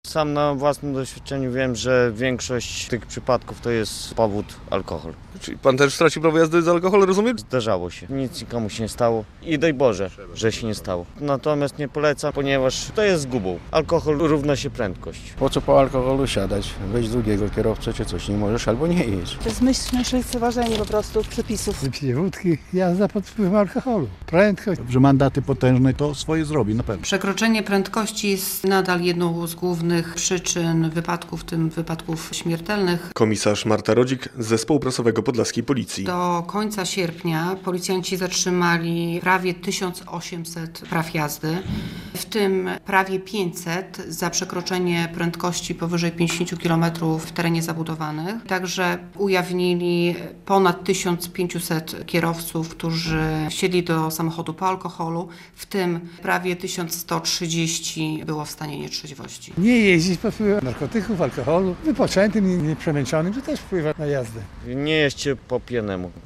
Grzechy główne kierowców - relacja